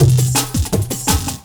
35 LOOP01 -L.wav